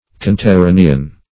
Search Result for " conterranean" : The Collaborative International Dictionary of English v.0.48: Conterranean \Con`ter*ra"ne*an\, Conterraneous \Con`ter*ra"ne*ous\, a. [L. conterraneus; con- + terra country.] Of or belonging to the same country.